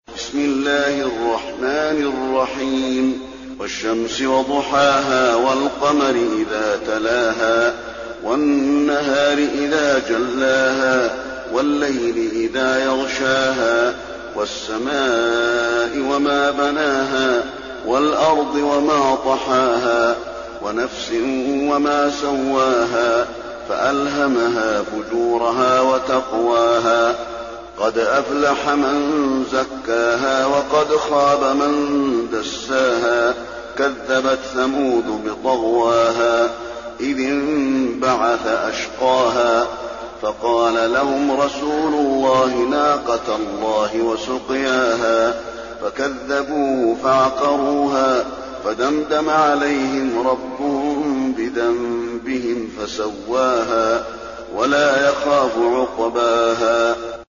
المكان: المسجد النبوي الشمس